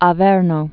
(ä-vĕrnō) Ancient name A·ver·nus (ə-vûrnəs)